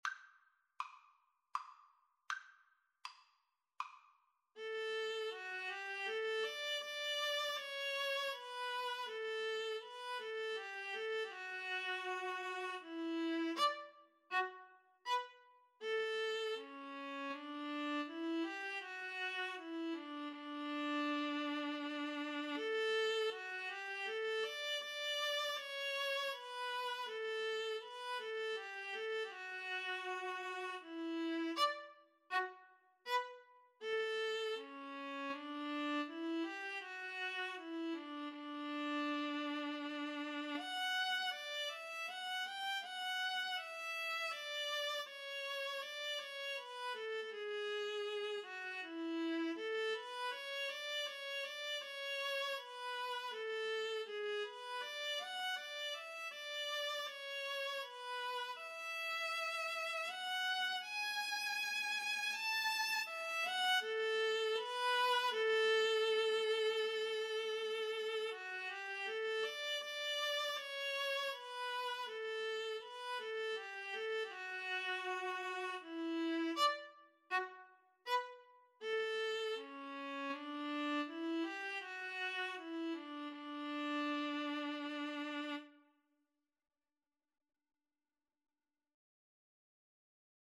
D major (Sounding Pitch) (View more D major Music for Viola Duet )
Andante
Viola Duet  (View more Easy Viola Duet Music)
Classical (View more Classical Viola Duet Music)